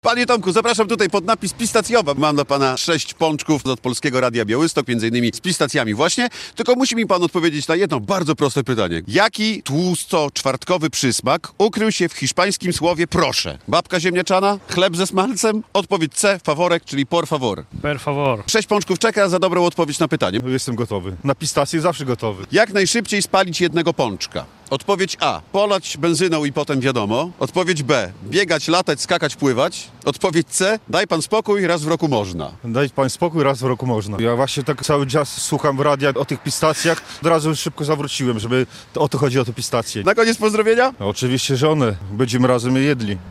Pierwsze pączki trafiły w ręce Słuchaczy Polskiego Radia Białystok - relacja